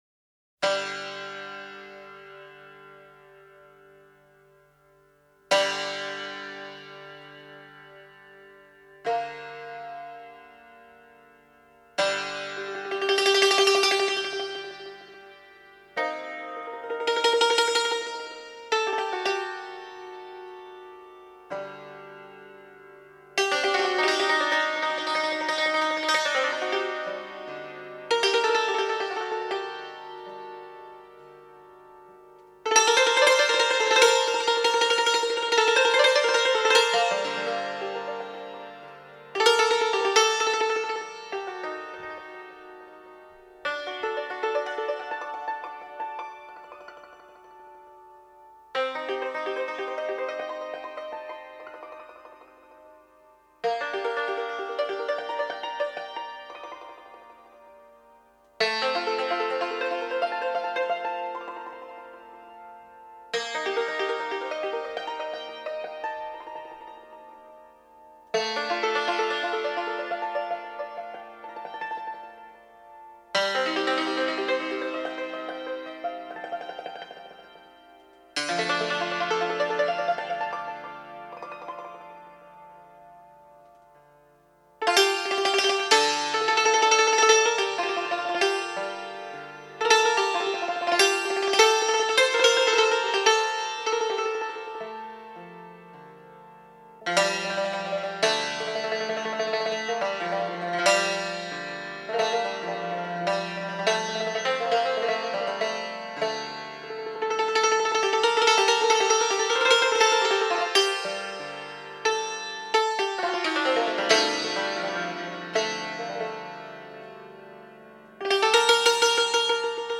سبک : بی کلام